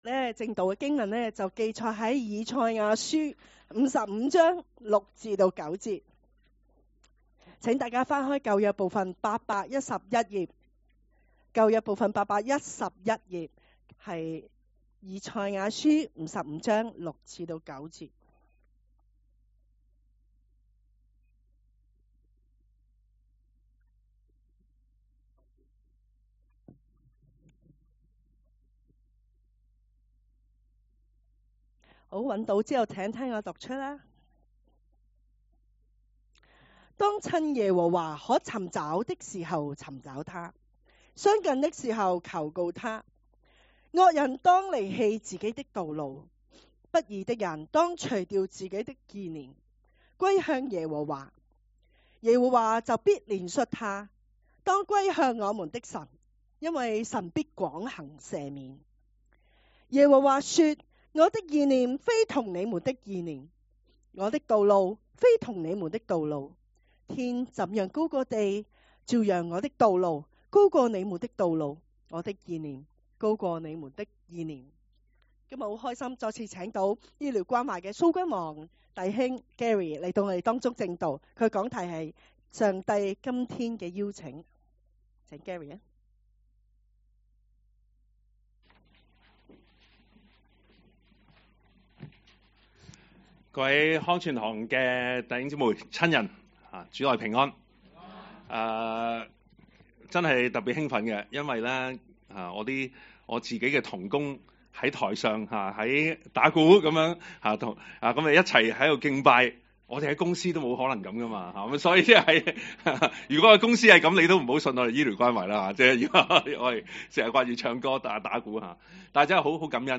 2024年12月14日及15日講道
崇拜講道